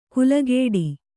♪ kulagēḍi